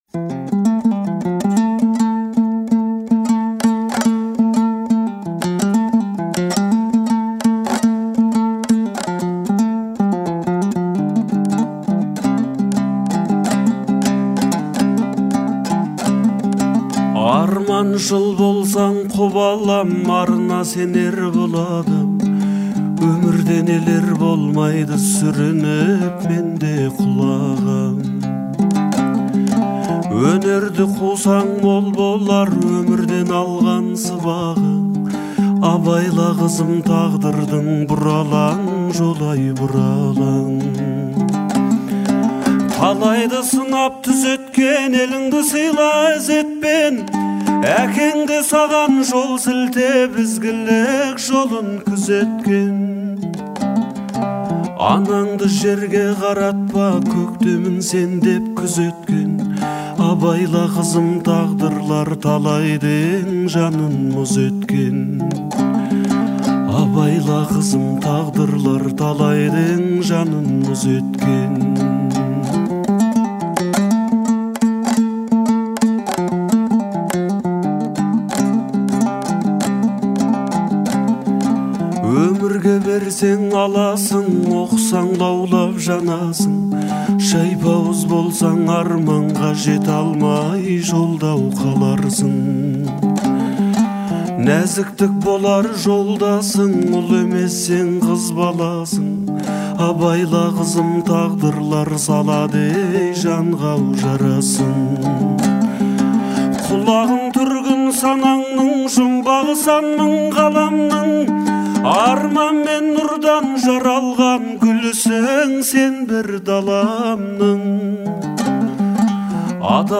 это трогательная песня в жанре казахской народной музыки